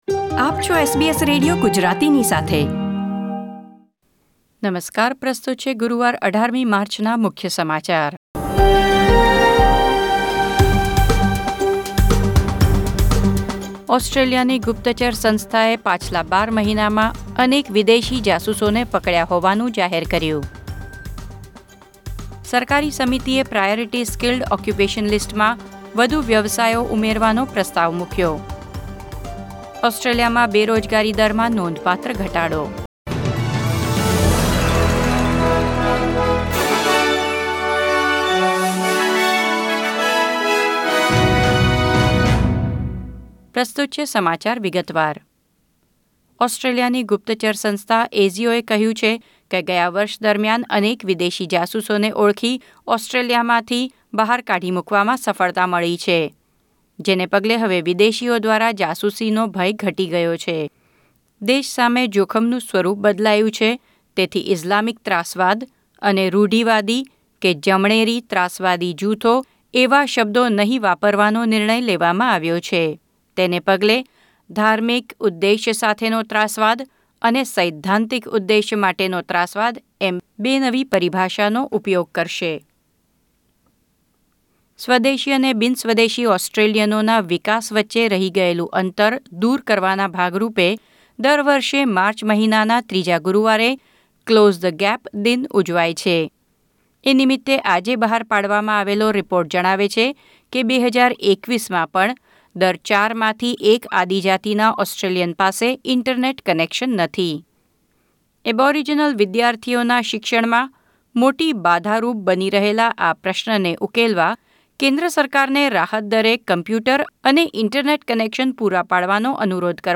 SBS Gujarati News Bulletin 18 March 2021